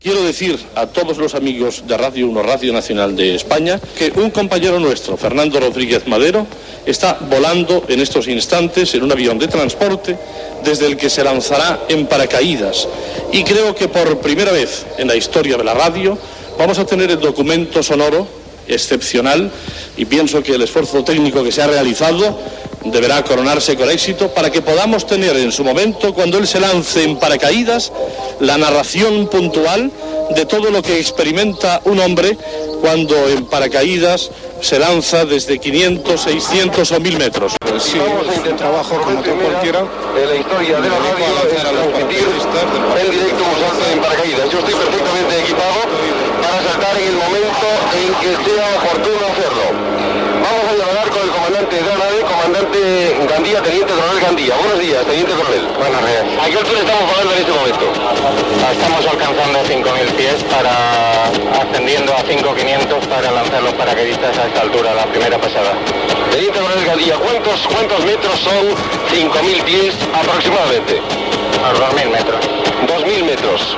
Primera connexió amb l'avió.
Info-entreteniment